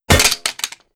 Weapon_Drop 03.wav